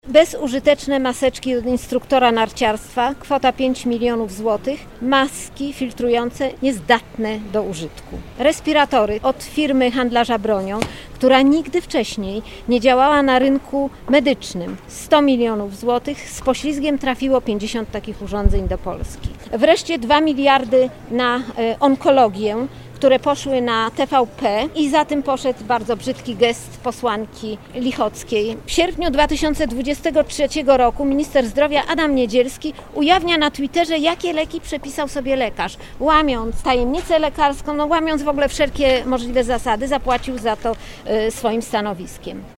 W 2020 roku rząd wydał ogromne pieniądze na zakup środków ochrony zdrowia, które okazały się niezdatne do użycia. – przypomina senator, prof. Alicja Chybicka.